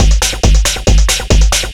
DS 138-BPM B3.wav